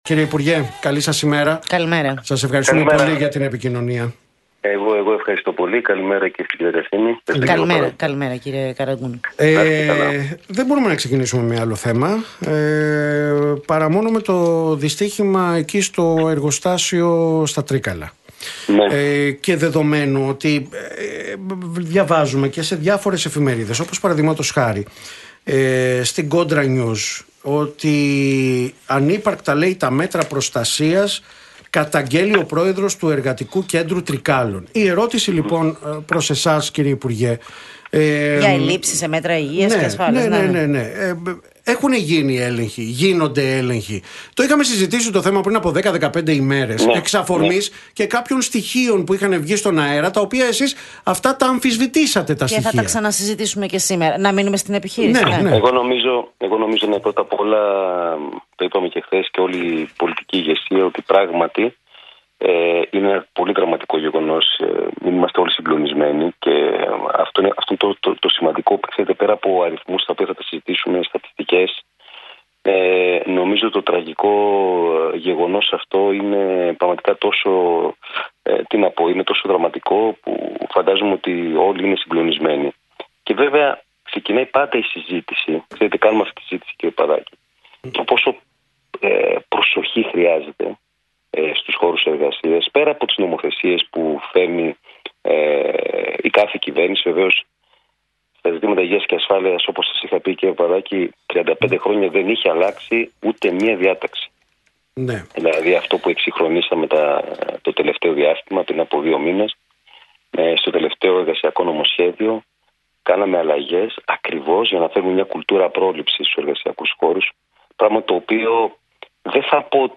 όπως αποκάλυψε ο υφυπουργός Εργασίας Κώστας Καραγκούνης μιλώντας στον Realfm 97,8